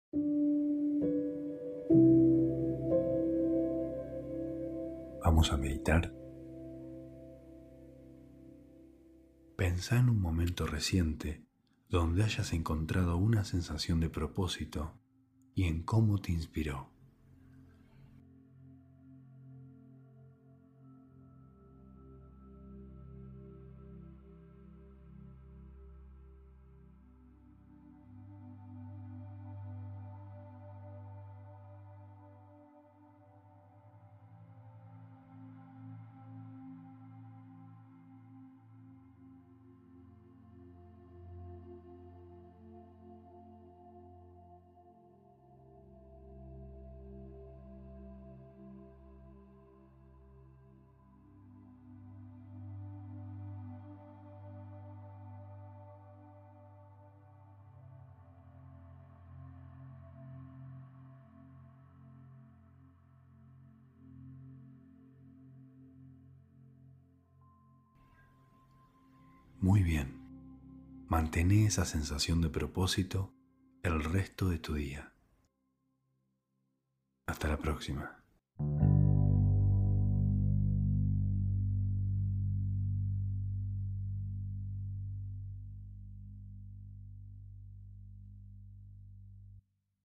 Meditación de 1 minuto para reflexionar.